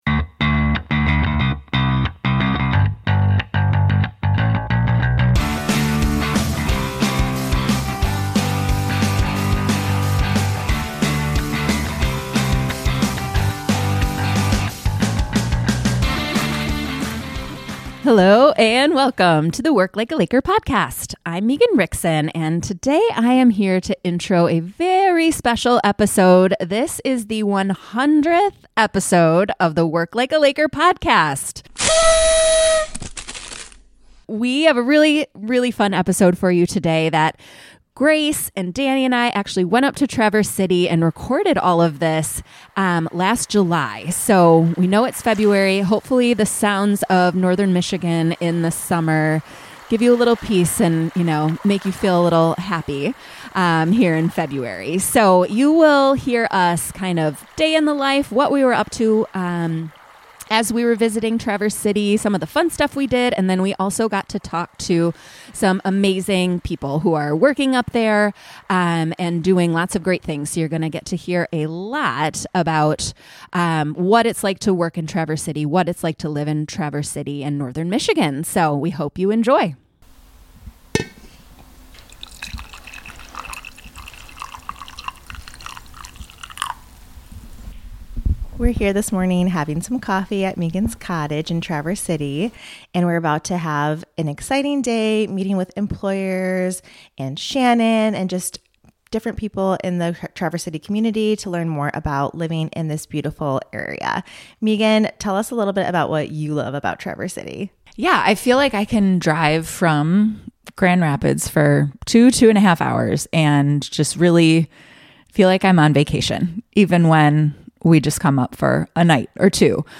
That’s right— for the first time ever, this podcast is going on the road!